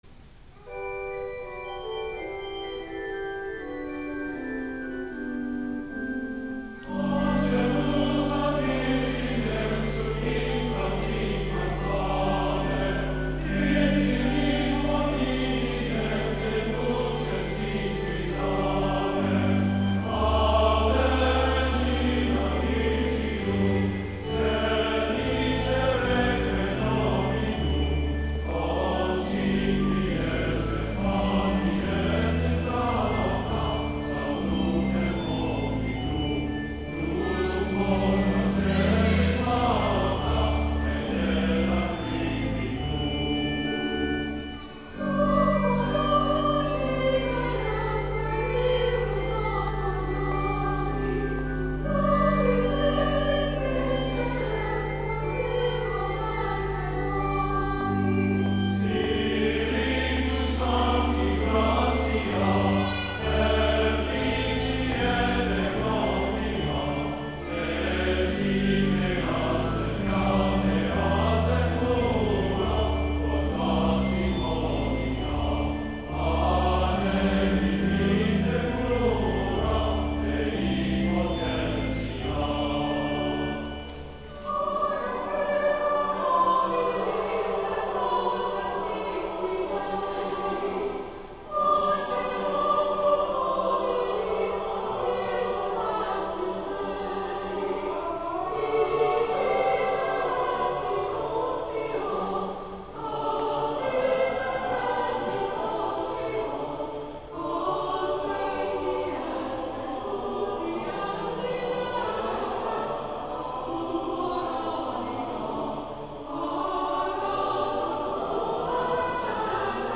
Christmas Eve
Holy Eucharist
Cathedral Choir